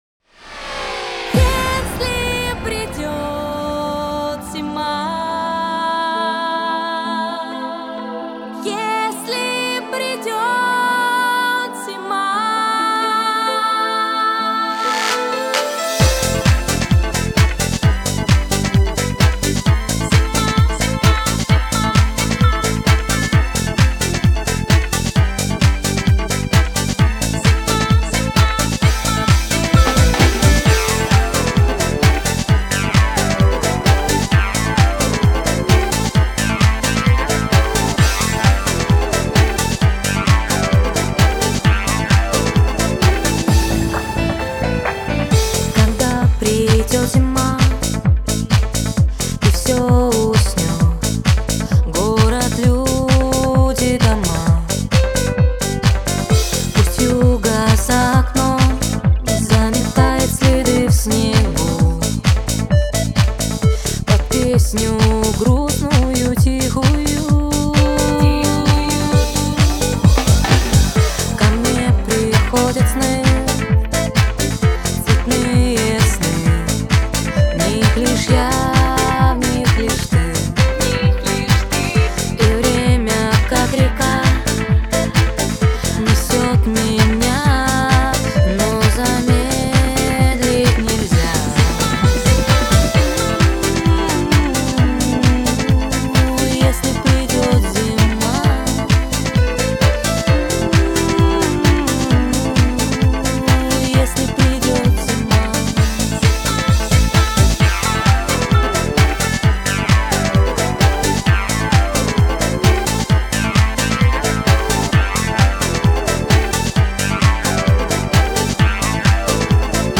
ремикс
snowdance mix